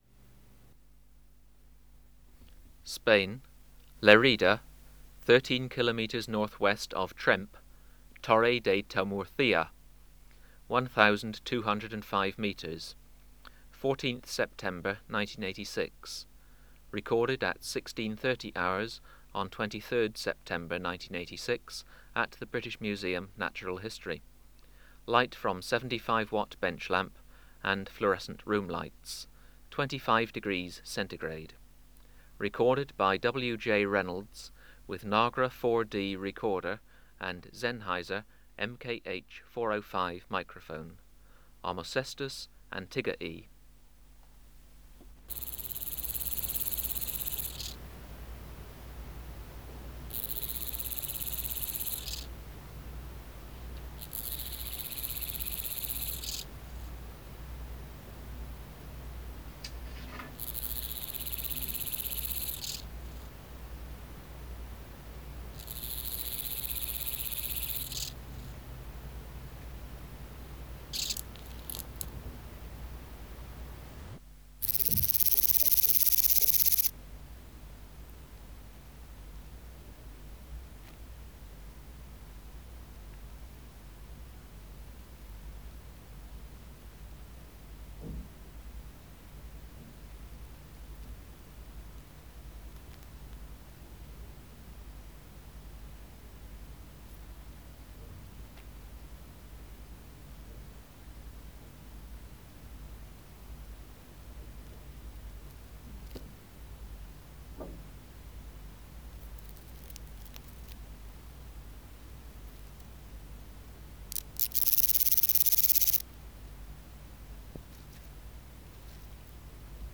Species: Omocestus (Dreuxius) antigai
Recording Location: BMNH Acoustic Laboratory
Substrate/Cage: Small recording cage
Courting a female and making noises when she walked over him. When microphone distance from the subject was 8 cm, courtship not good.
Filter: Low Pass, 24 dB per octave, corner frequency 20 kHz